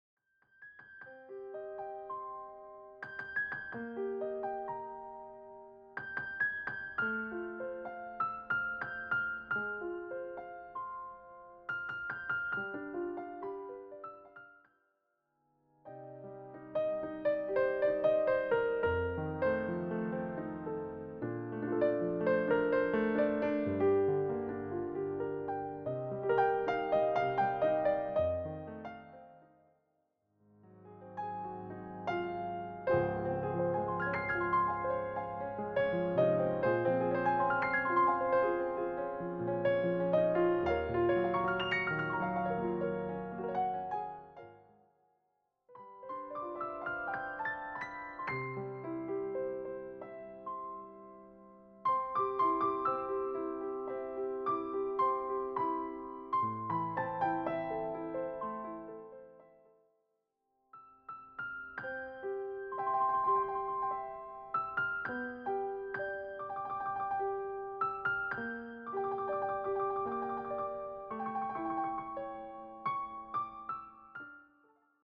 warm and familiar piano arrangements
solo piano